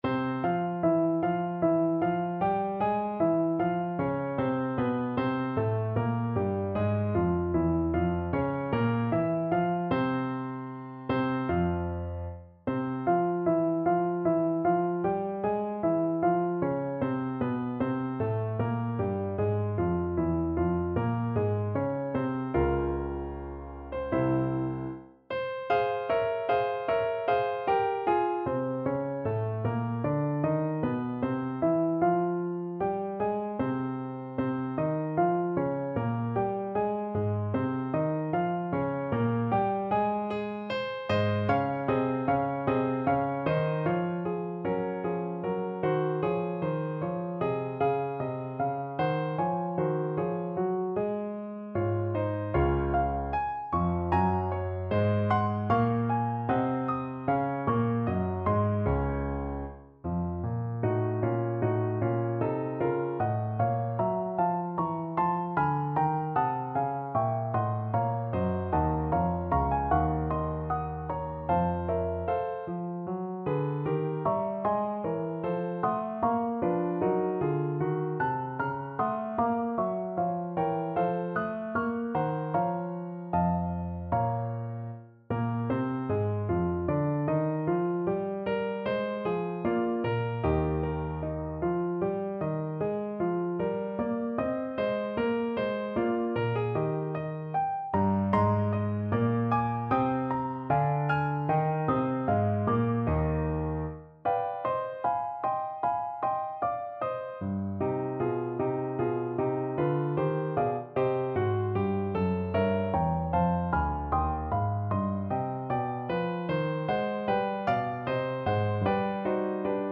Play (or use space bar on your keyboard) Pause Music Playalong - Piano Accompaniment Playalong Band Accompaniment not yet available reset tempo print settings full screen
F minor (Sounding Pitch) C minor (French Horn in F) (View more F minor Music for French Horn )
Larghetto (=76)
Classical (View more Classical French Horn Music)